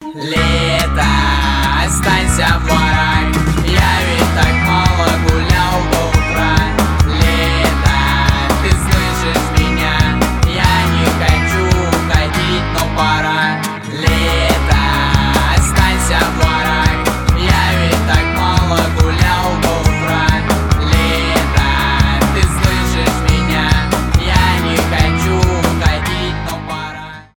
рок , альтернатива , акустика